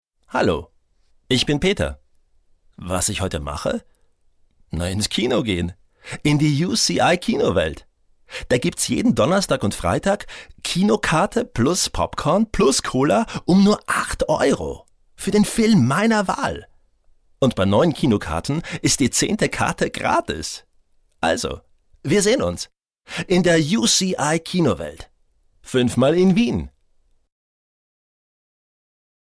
Sprachdemos